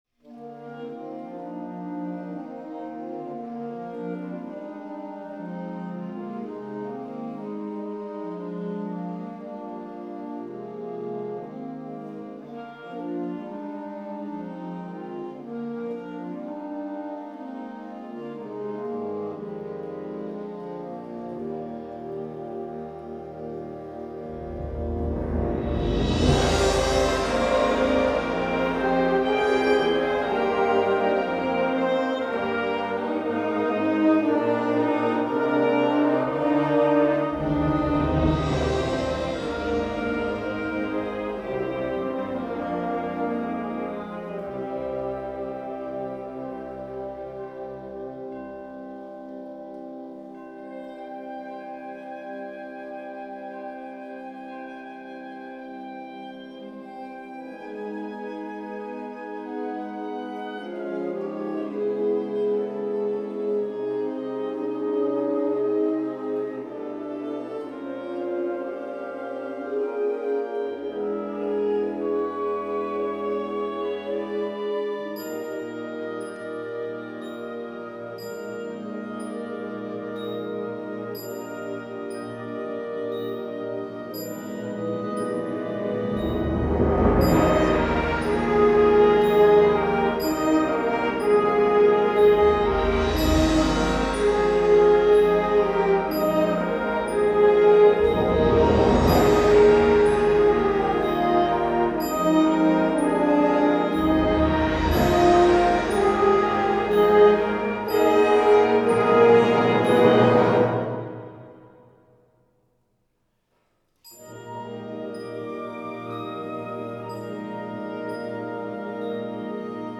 編成：吹奏楽
Tuba
Bells
Chimes
Gong